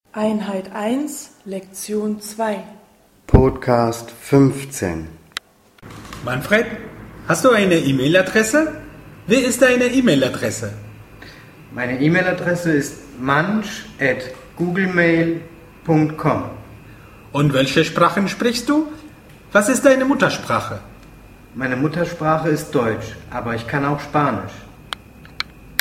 Dialog 4